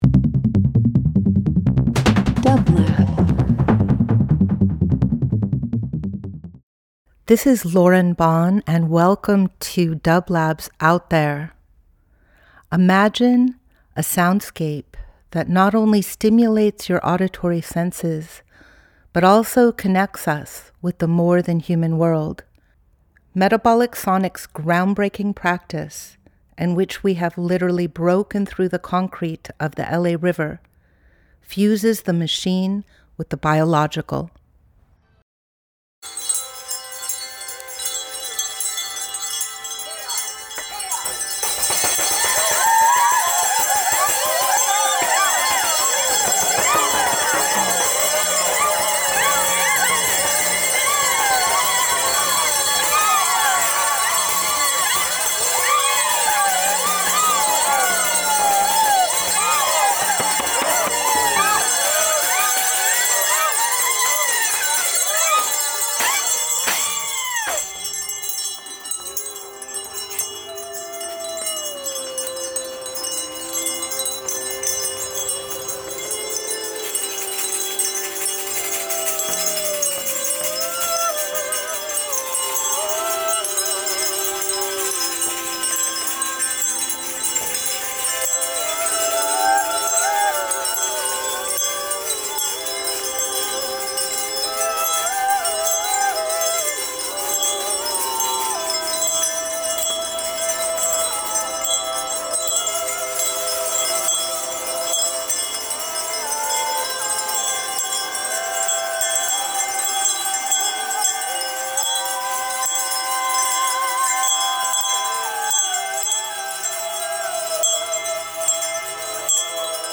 Each week we present field recordings that will transport you through the power of sound.
This week we’re sharing a field recording of the recent Friday the 13th Optimists Day Procession with the Community from Metabolic Studio to the Moon.